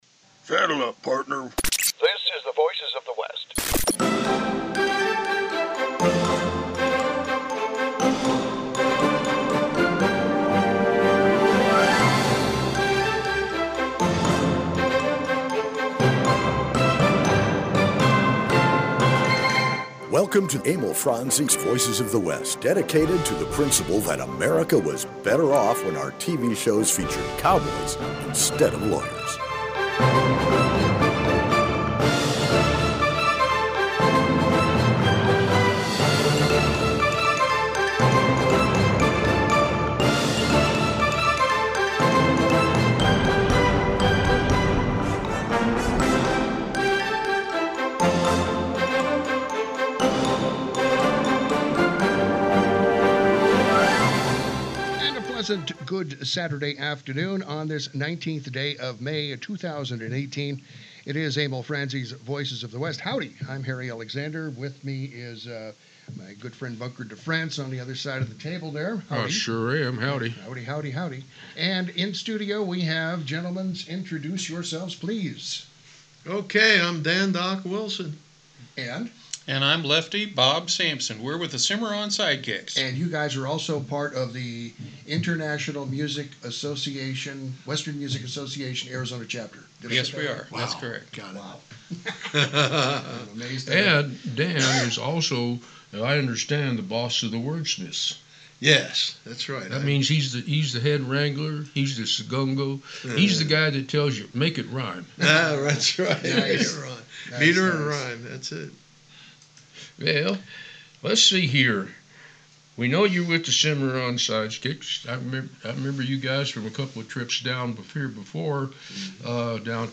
Also, poetry readings.